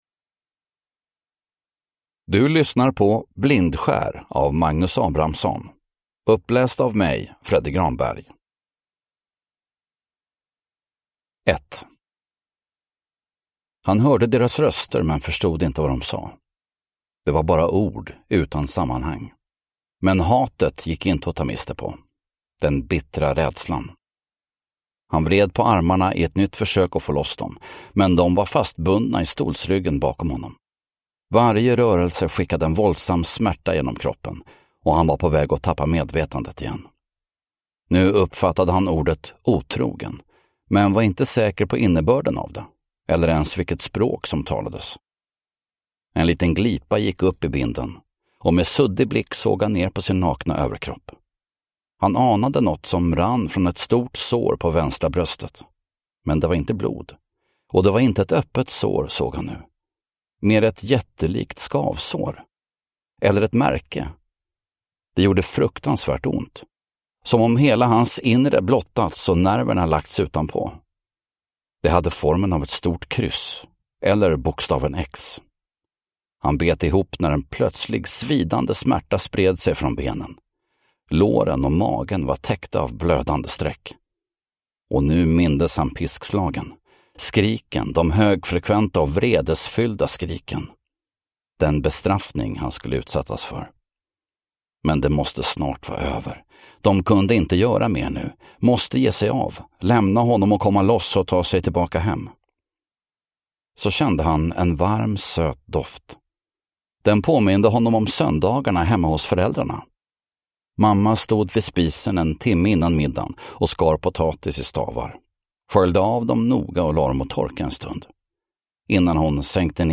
Blindskär – Ljudbok
Deckare & spänning Njut av en bra bok